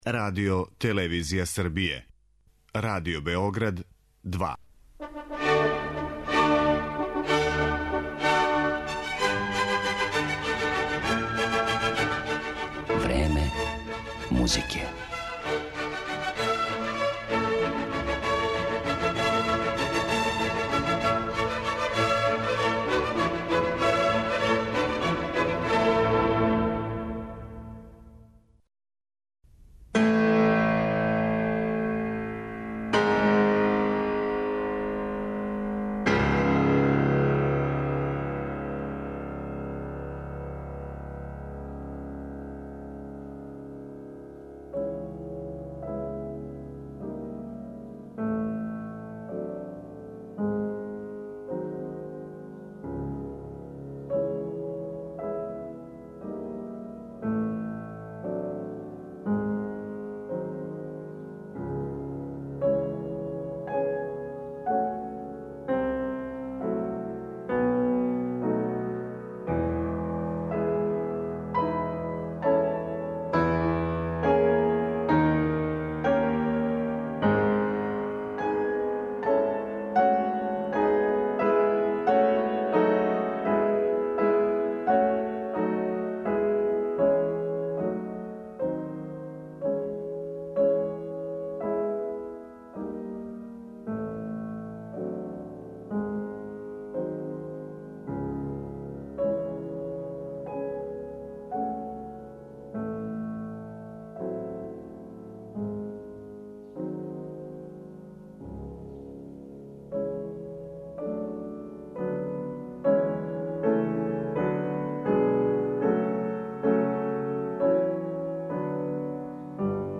Искреност, спонтаност, природност, једноставност